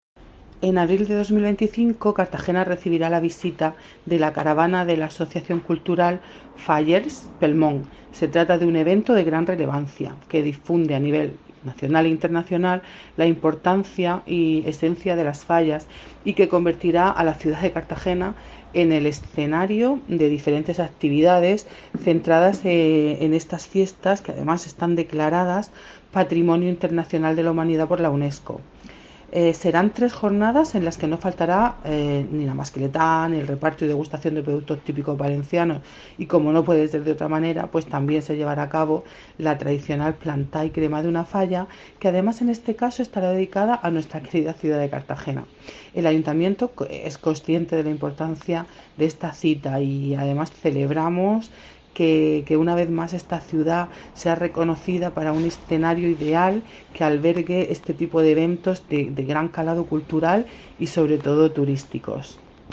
Enlace a Declaraciones de la concejal de Turismo, Beatriz Sánchez, sobre la plantá de la falla en Cartagena